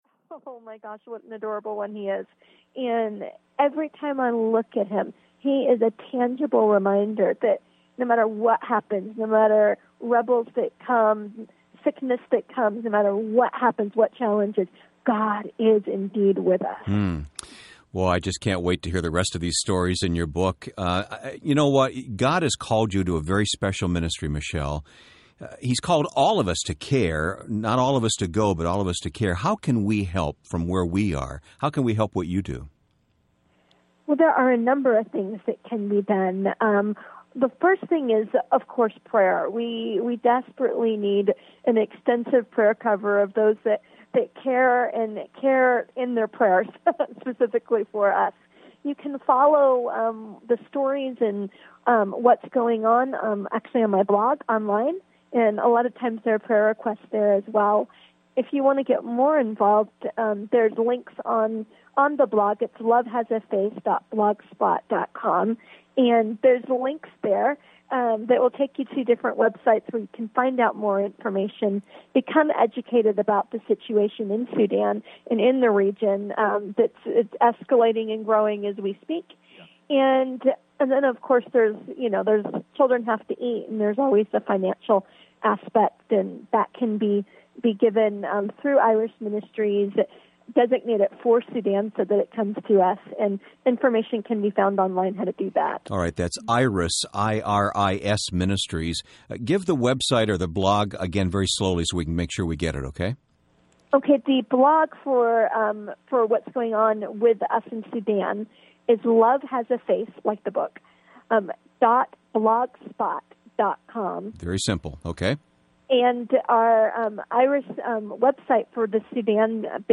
Love Has a Face Audiobook
Narrator
Rebecca St. James
6 Hrs. – Unabridged